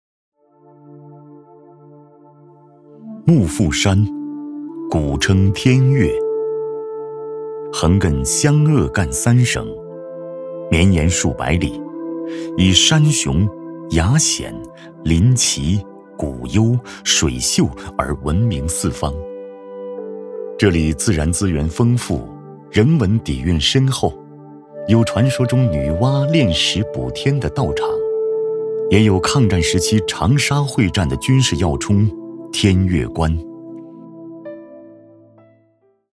旅游宣传片配音